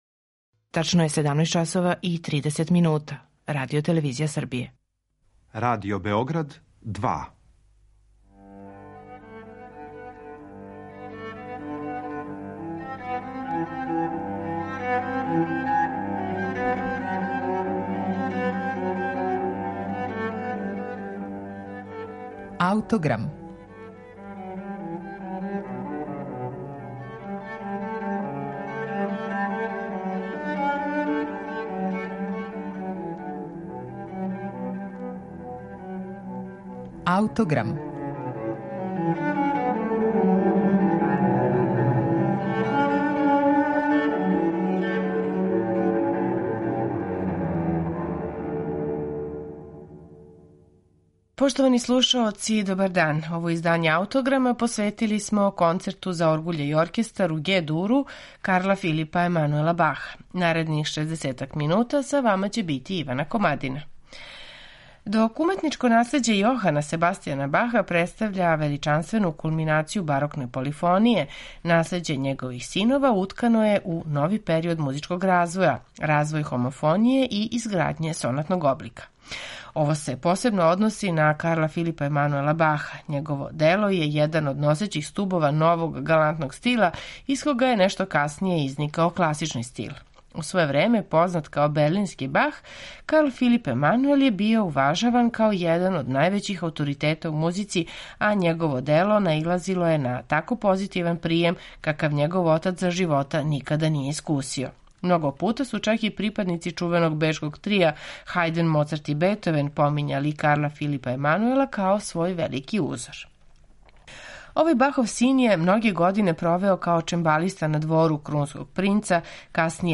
Као солистички инструмент у овом концерту, оргуље су аутору пружиле могућност за знатно сложенију палету динамичког израза од оне коју показују његове ране композиције. Имајући у виду разиграни, галантни стил који превладава оквирним ставовима овог дела, а посебно емотивну занесеност средишњег Ларга, овај концерт могуће је посматрати и као далеки наговештај знатно касније насталих романтичарских оргуљских концерата. Концерт за оргуље и оркестар у Г-дуру Карла Филипа Емануела Баха слушаћемо у интерпретацији Мари-Клер Ален и Камерног оркестра Жан-Франсоа Пајар.